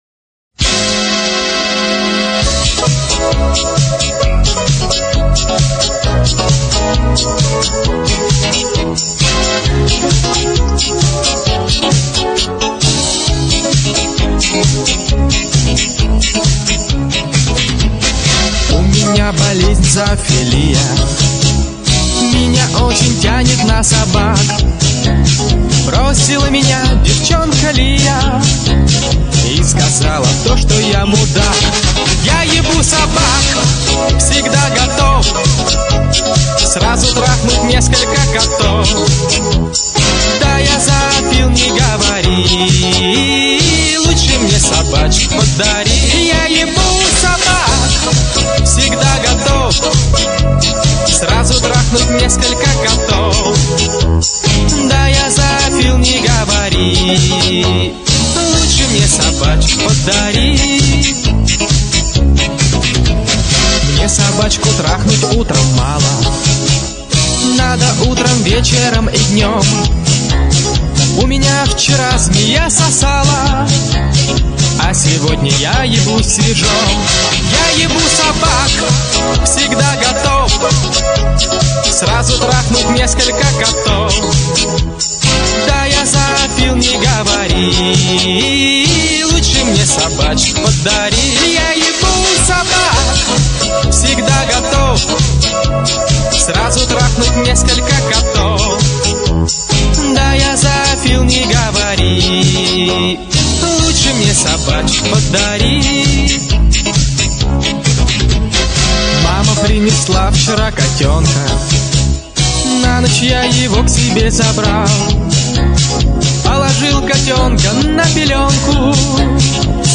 dog.mp3